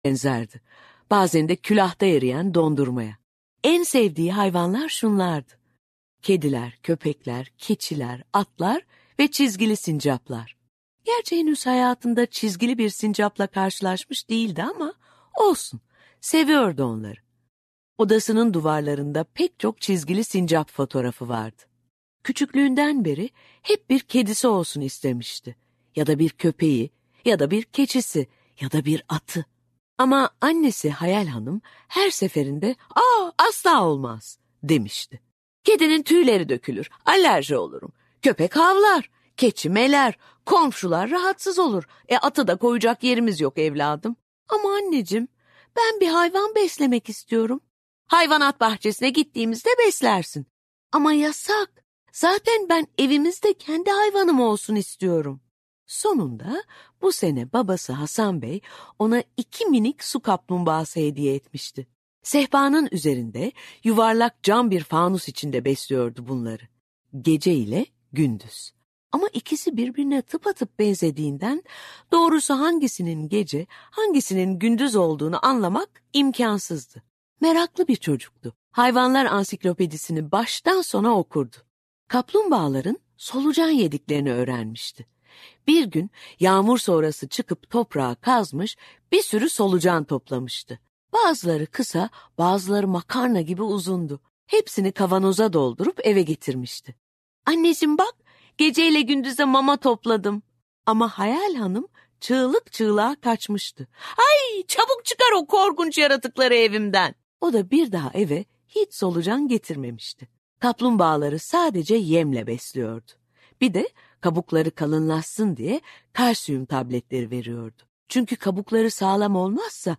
Seslendiren
TİLBE SARAN